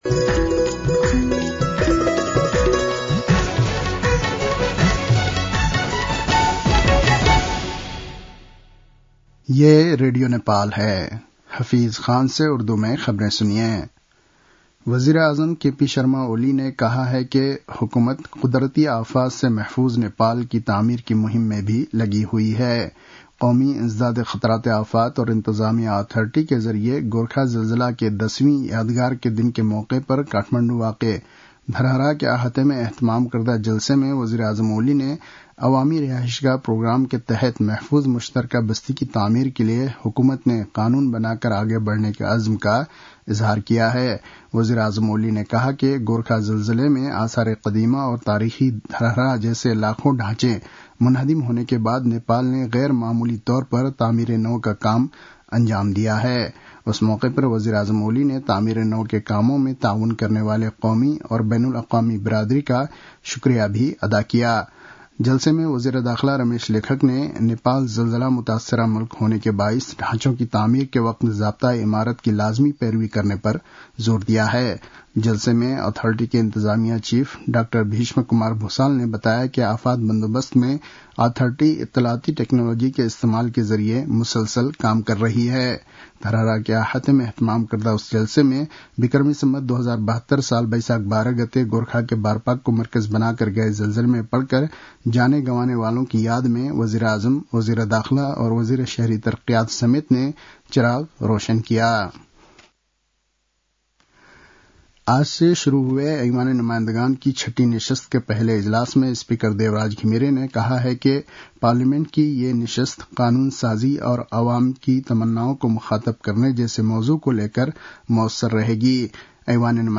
उर्दु भाषामा समाचार : १२ वैशाख , २०८२